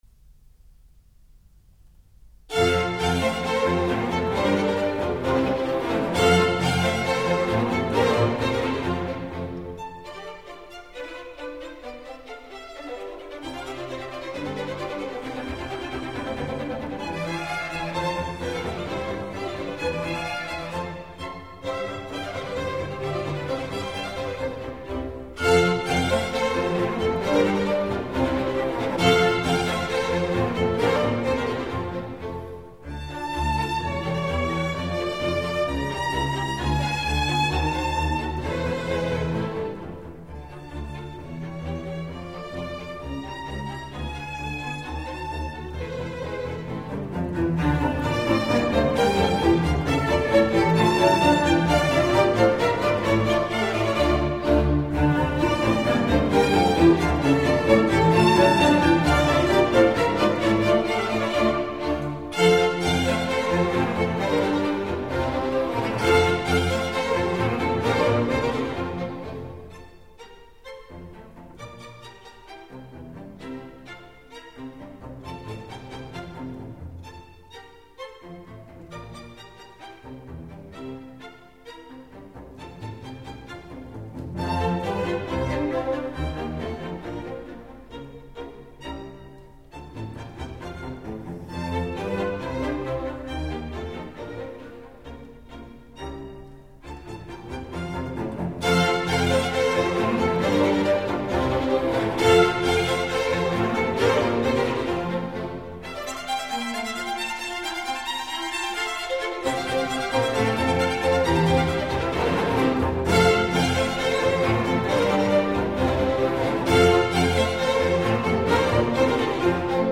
Presto